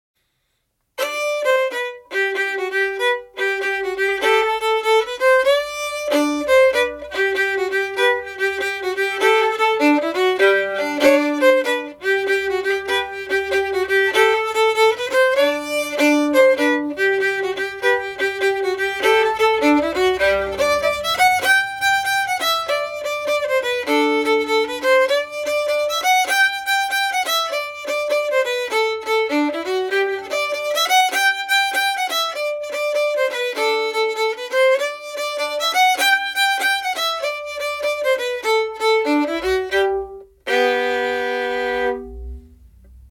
Hen’s Feet and Carrots is the melody to a Danish folk song
Hen’s Feet and Carrots melody – 2024 version (audio mp3)Download